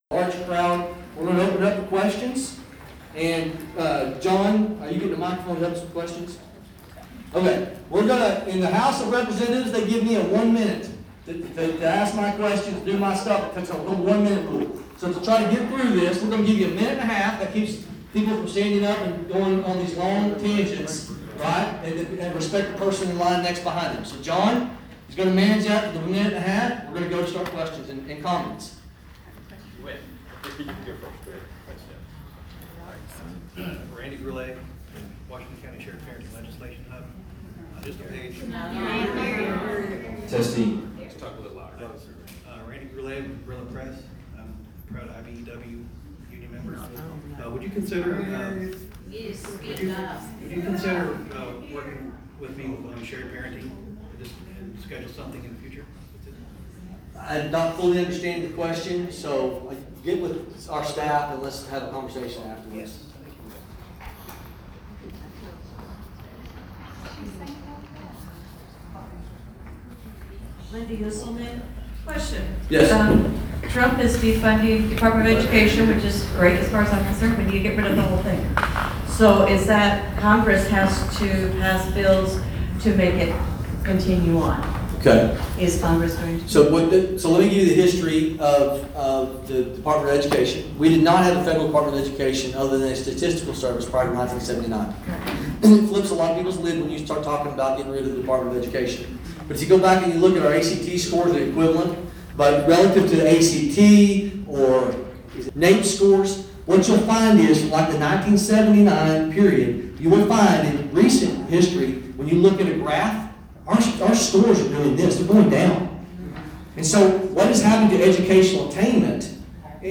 A woman stated her support of ending the US Dept. of Education, something Brecheen supports.